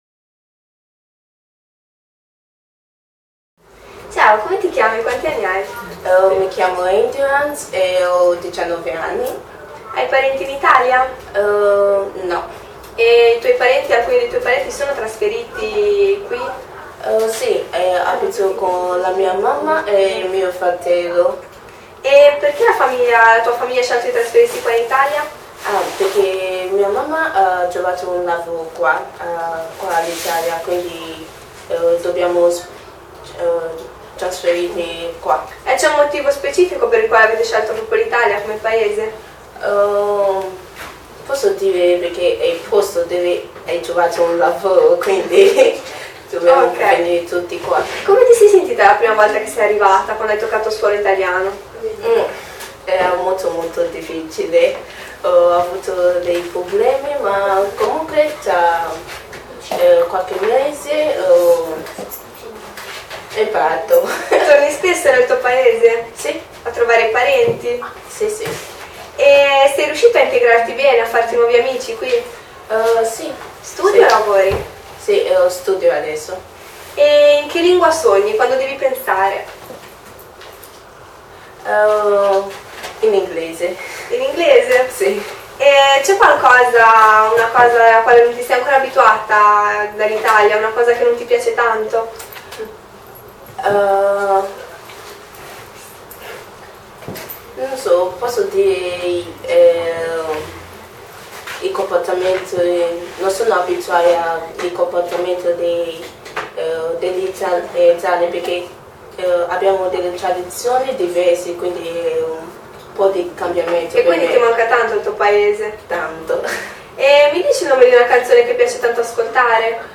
Intervista a Endurance